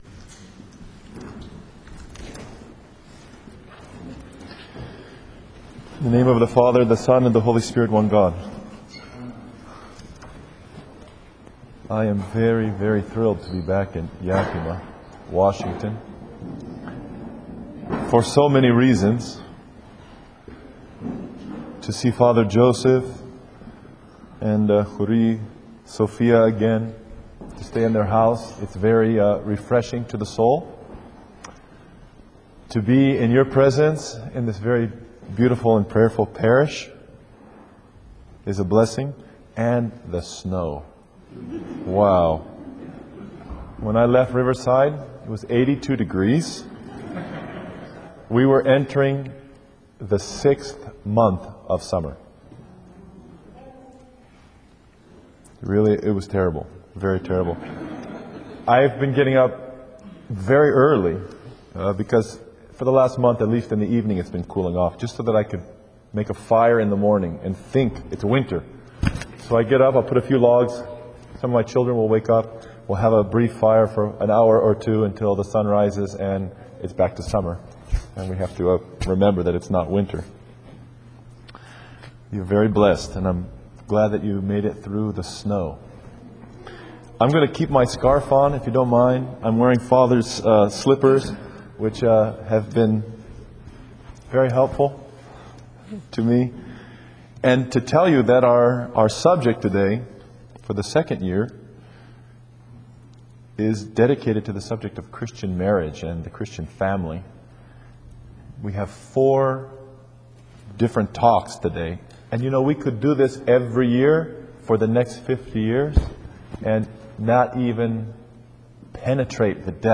The Christian Marriage and Family: Four Audio Lectures